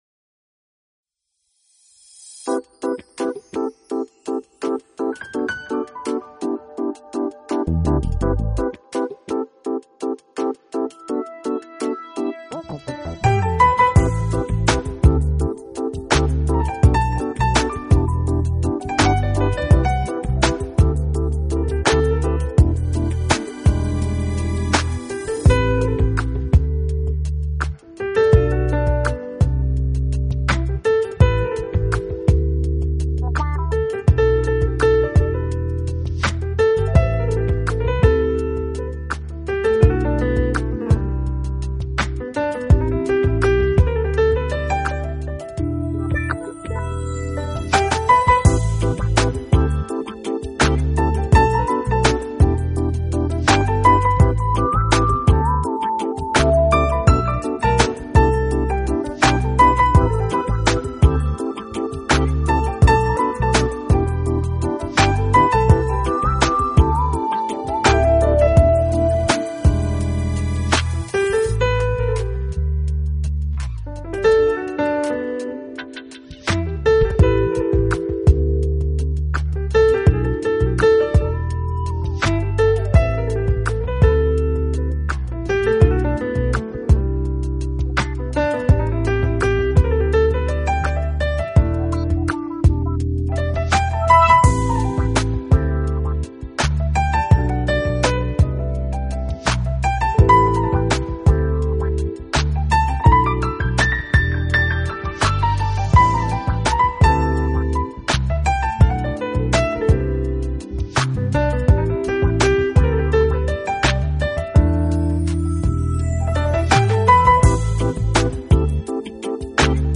音乐类型: Jazz / Smooth Jazz / Piano